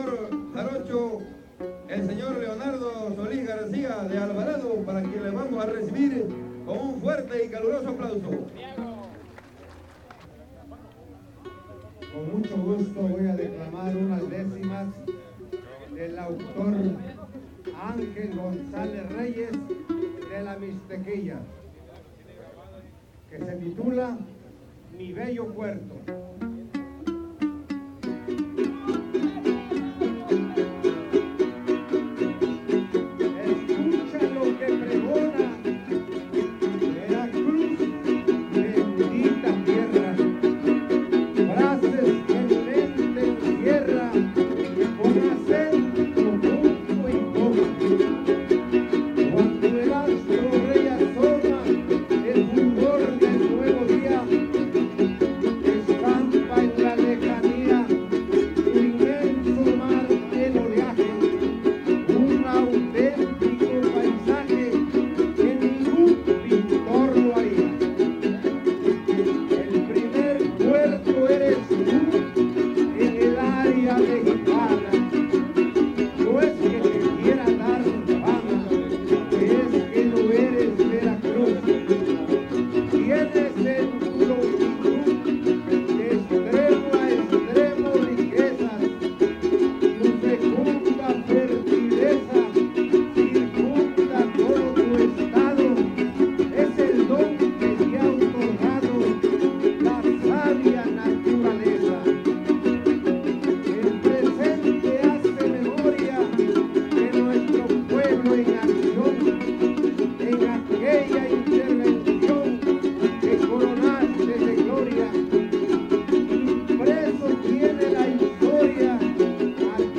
Fandango